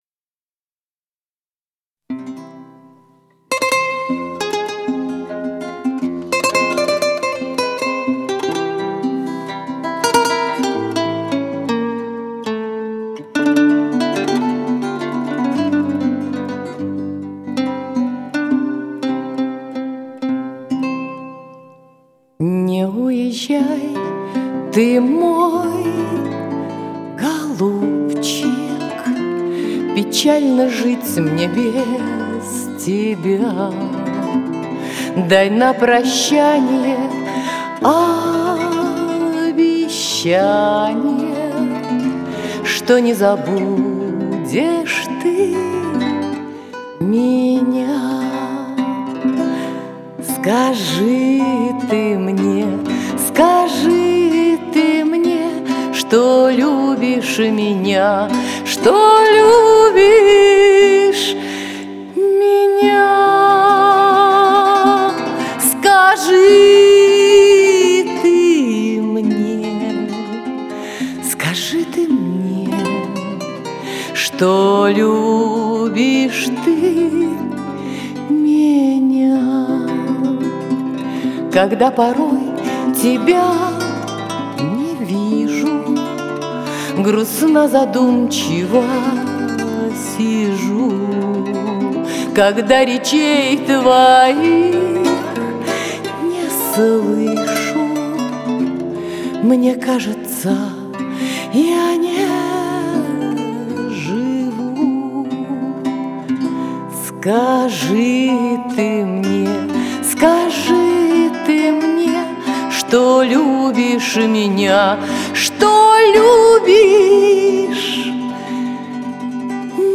Романсы, городской фольклор и народные песни.